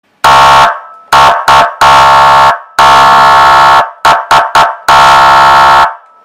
Звуки полицейской крякалки
Звук спецсигналу кортежу або працівників поліції (СГУ)